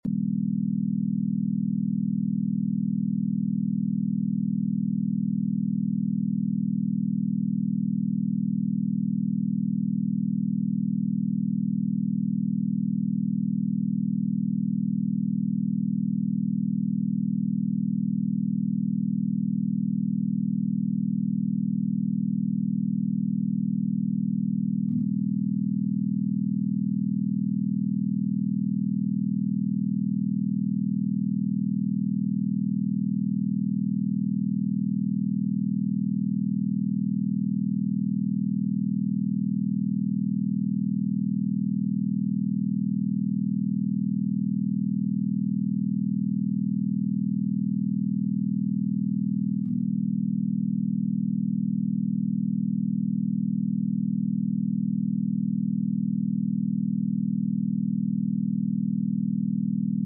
From Broke to Millions with 40 Hz BINAURAL Beats